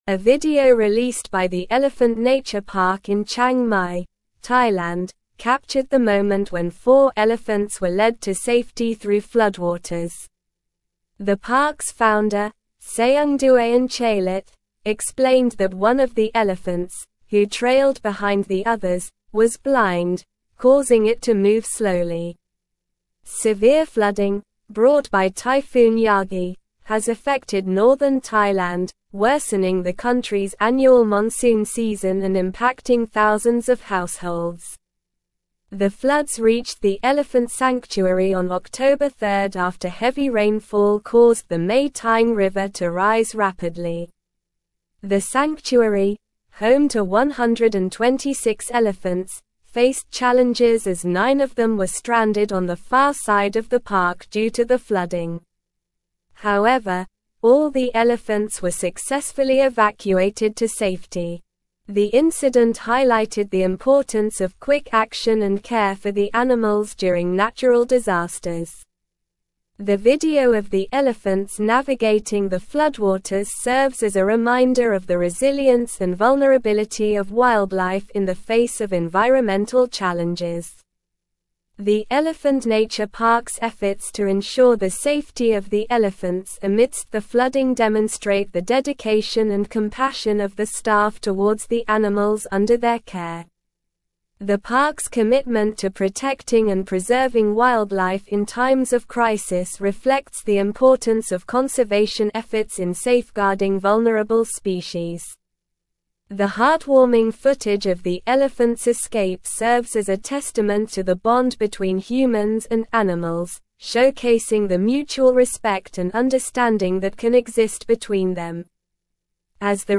Slow
English-Newsroom-Advanced-SLOW-Reading-Elephants-rescued-from-floodwaters-in-Thailand-sanctuary.mp3